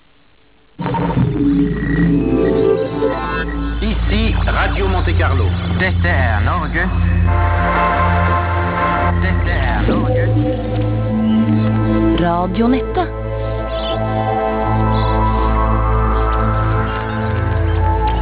vignett